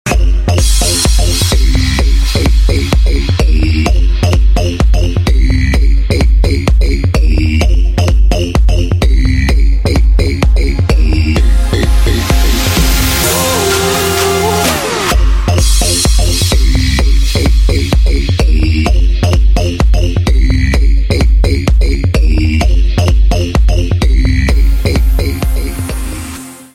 Ритмичный club house